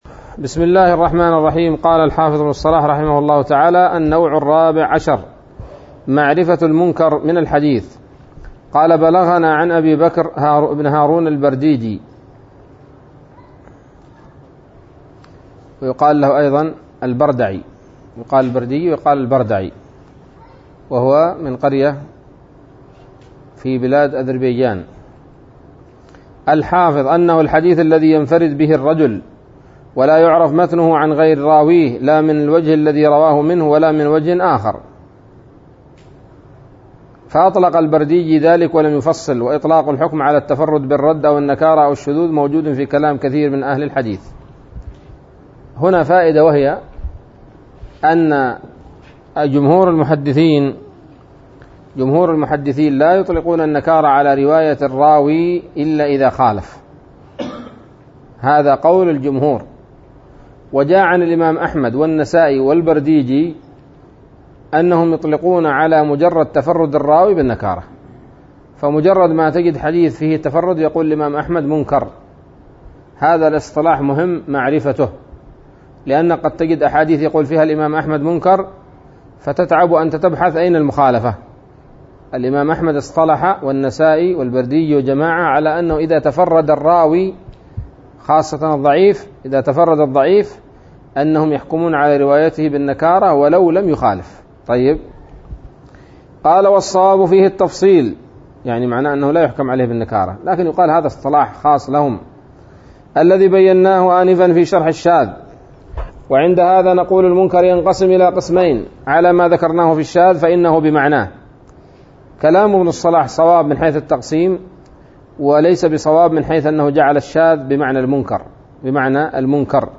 الدرس الرابع والثلاثون من مقدمة ابن الصلاح رحمه الله تعالى